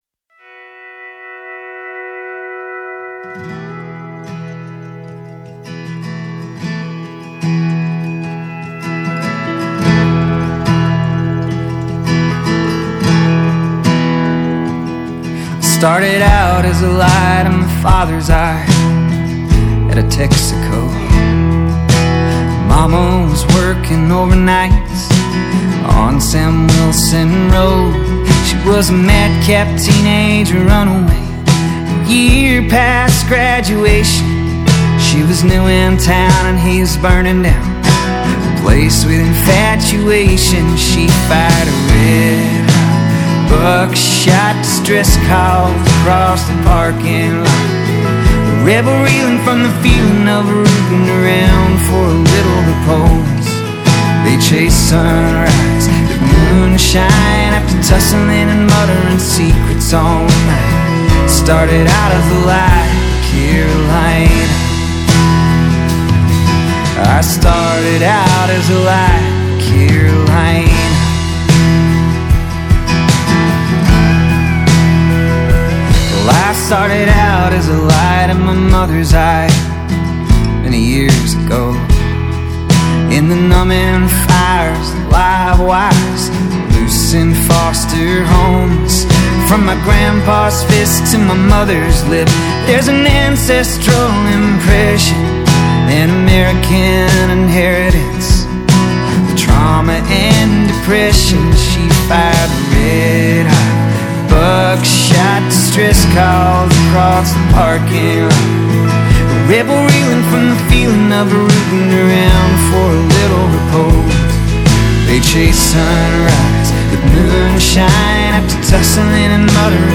Alternative Country, Americana